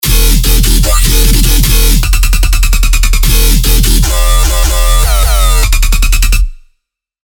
精心打造了这款包含 200 个震撼舞池的低音单音和低音循环的杰作。
期待这些超强 dubstep 低音，让你的 drop 更具冲击力！
所有采样均已标注调性，BPM 为 150。